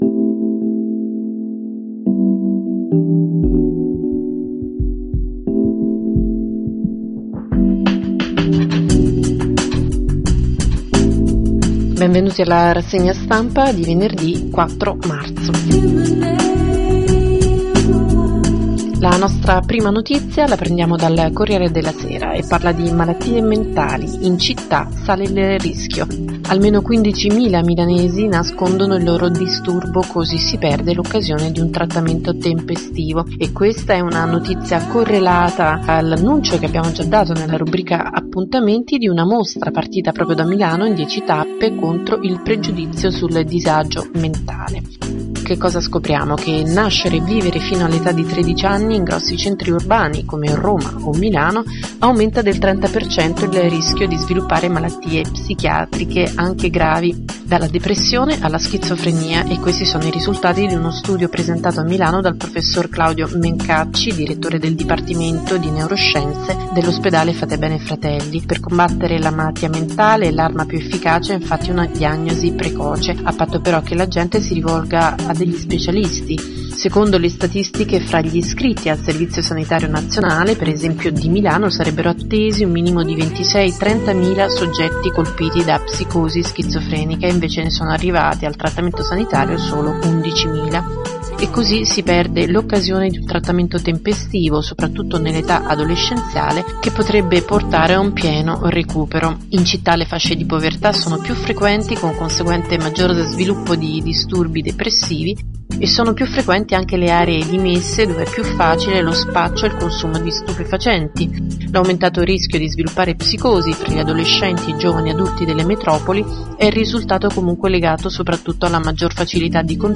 RASSEGNA STAMPA 4 MARZO 2011